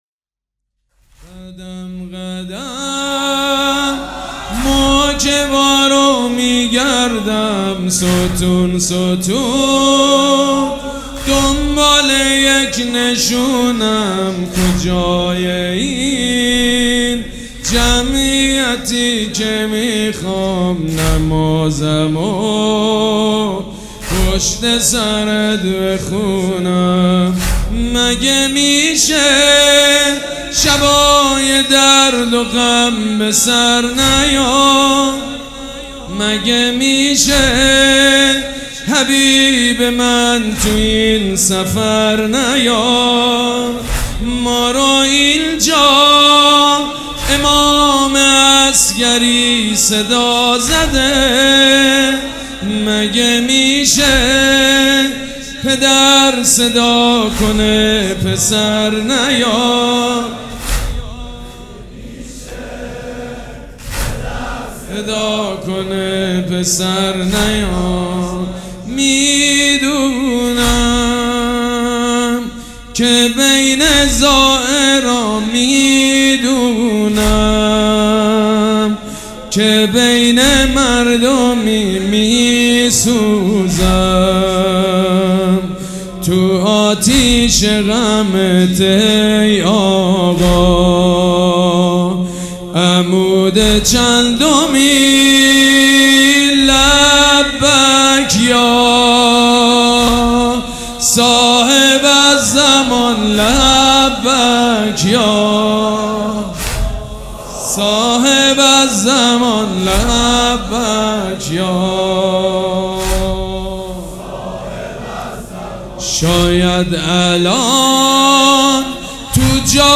مداح
حاج سید مجید بنی فاطمه
مراسم عزاداری شب سوم